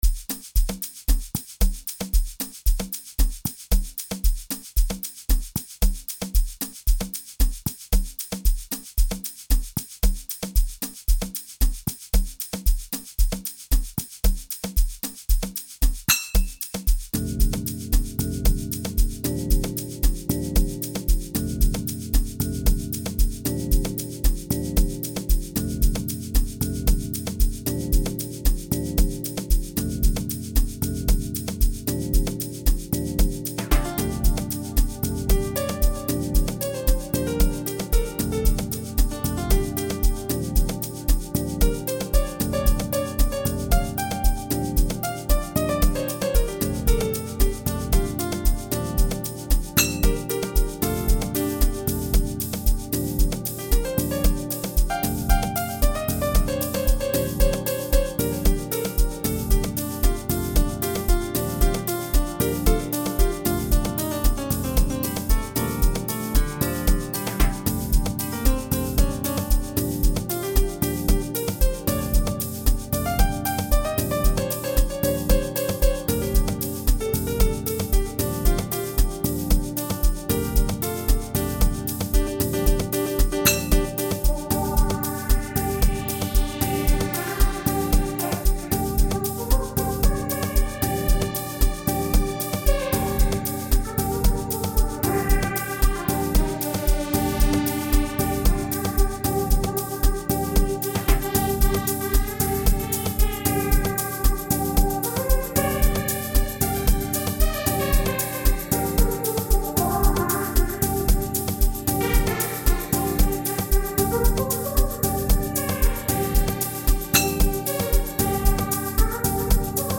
an Amapiano record with melodious tune and strings